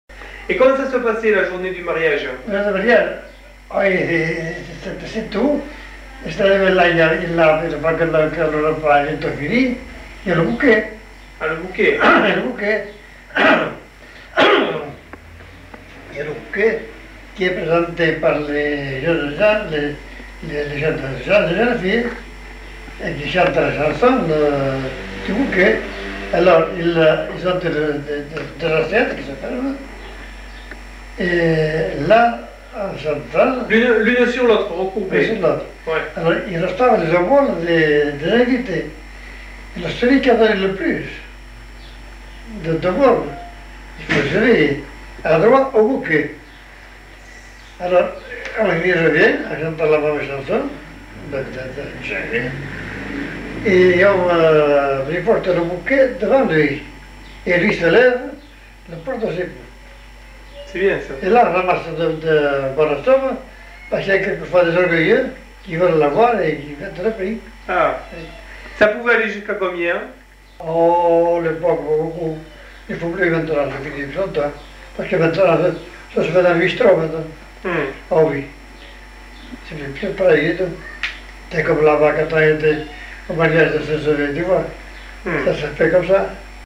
Aire culturelle : Petites-Landes
Genre : chant
Effectif : 1
Type de voix : voix d'homme
Production du son : chanté